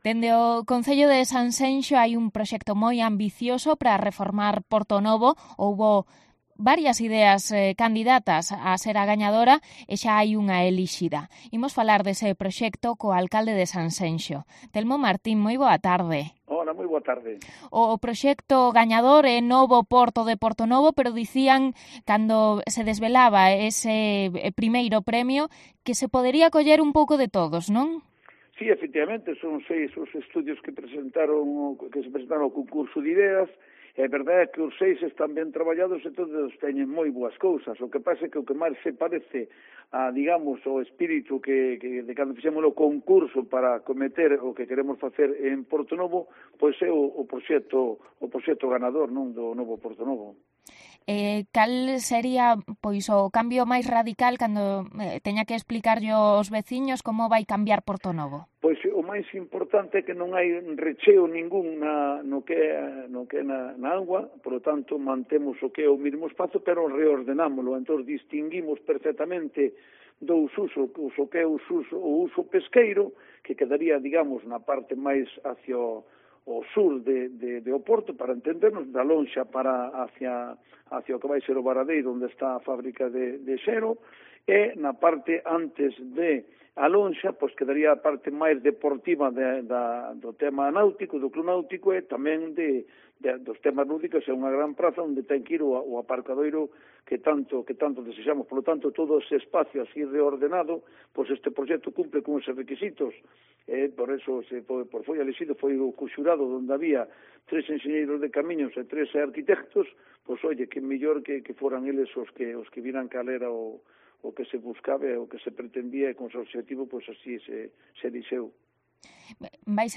Entrevista al alcalde de Sanxenxo, Telmo Martín, sobre la reforma de Portonovo